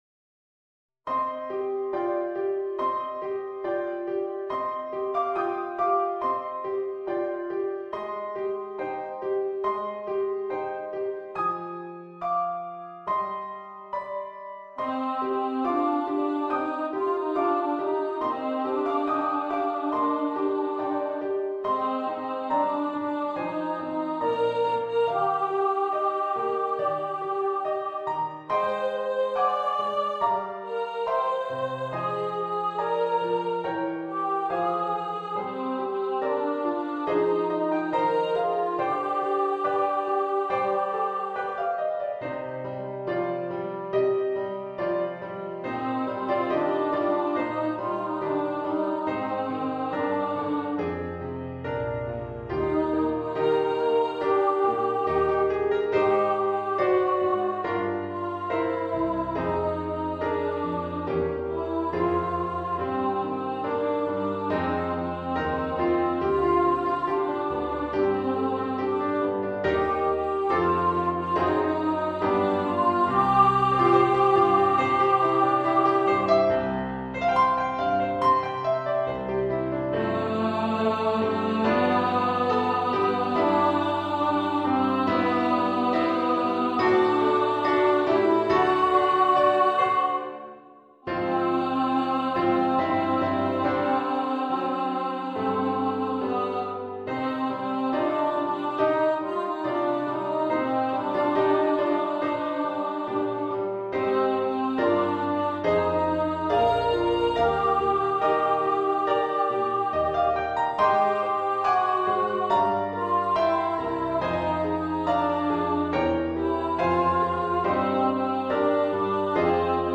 "Christmas Children Alto".